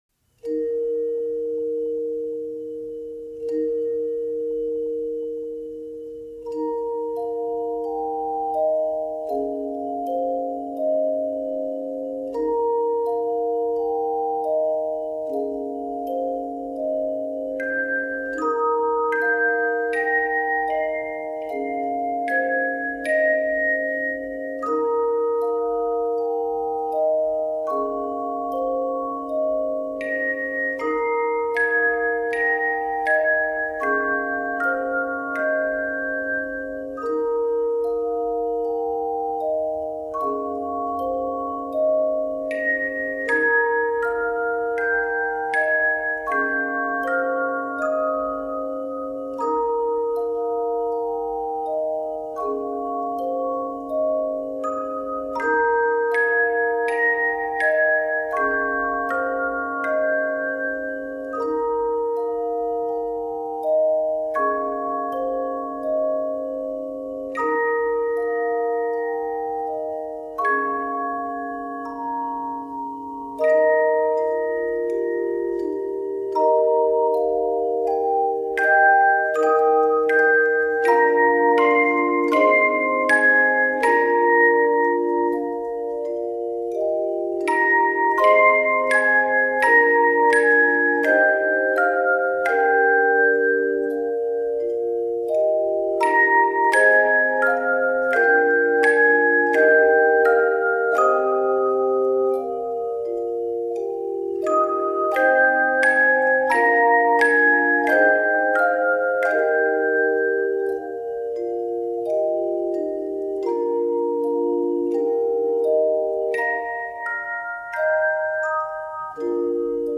This gentle, easily accessible level 1+ arrangement